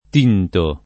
t&nto] — ant. o poet. tignere [t&n’n’ere]: tingo, tigni; con ‑gn‑ [n’n’] invece di ‑ng‑ [nJ] davanti a tutte le desinenze che comincino per e o per i